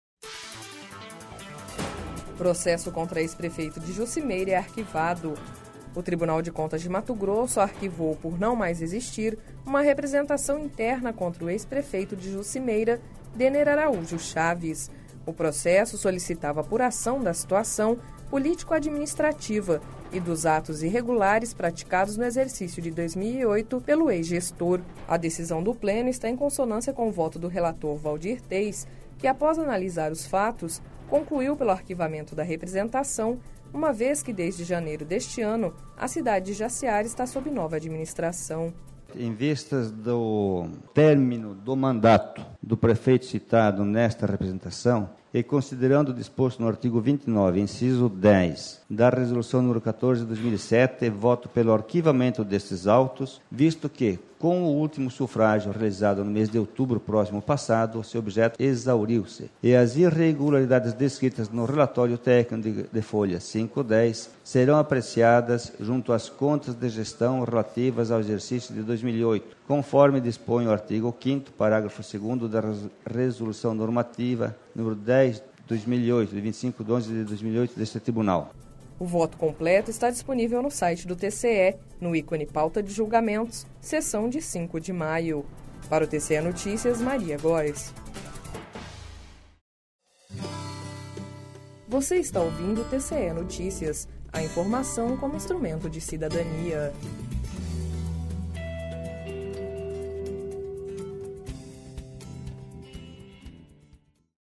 Sonora: Waldir Teis - conselheiro do TCE-MT